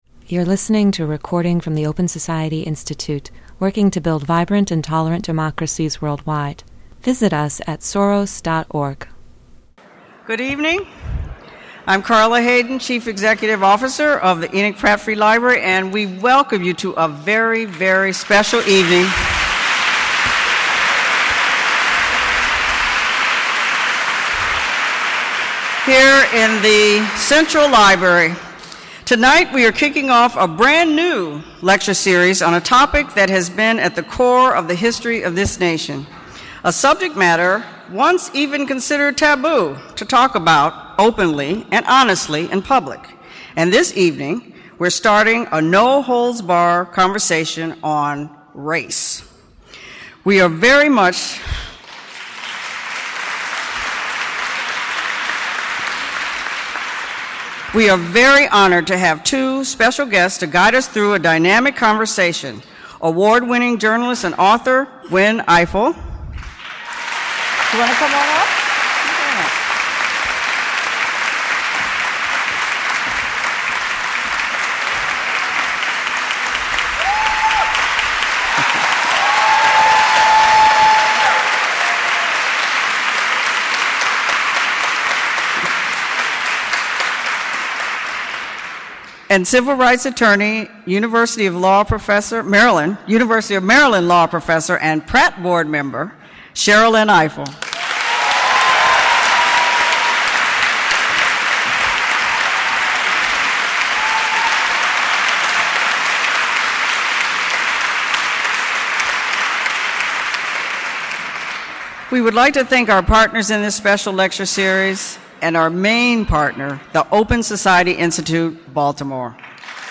Journalist Gwen Ifill and law professor Sherrilyn A. Ifill discuss this pivotal moment in American history and its potential for advancing equity and social justice.